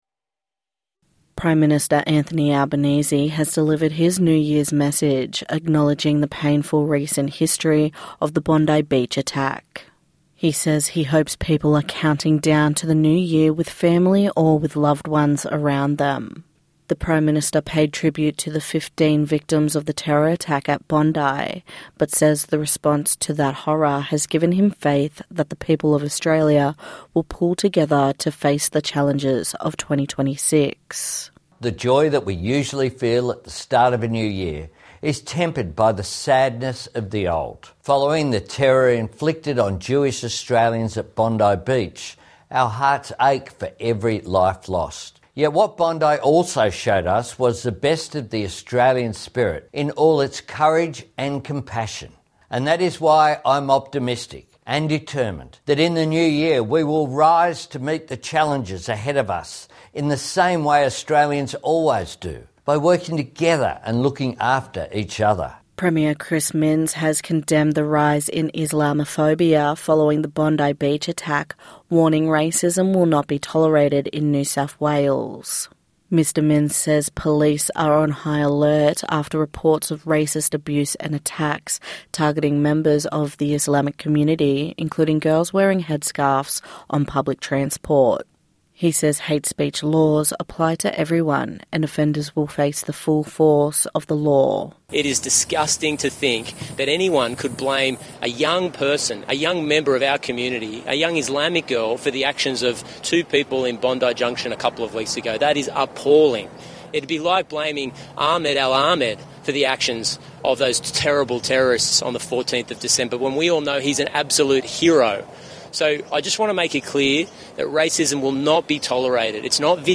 NITV Radio bringing the latest in news sport and weather.